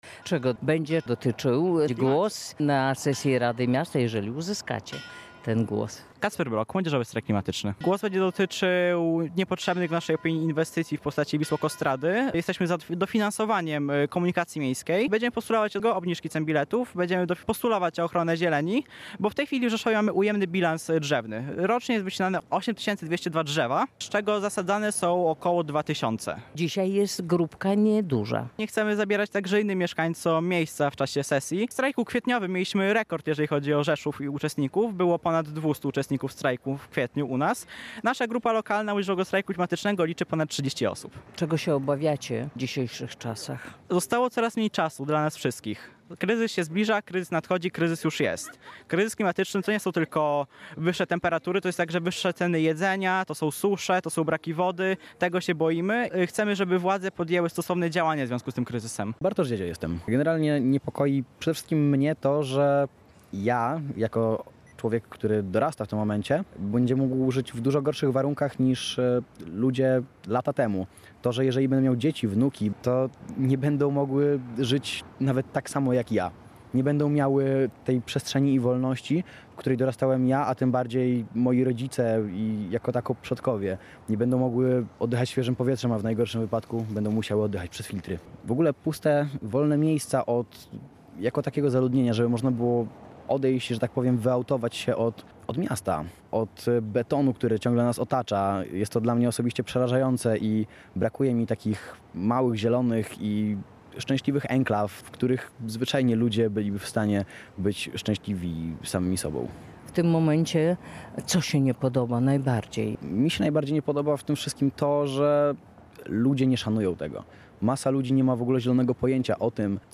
Młodzi ludzie trzymając w dłoniach transparenty uczestniczyli w sesji Rady Miasta.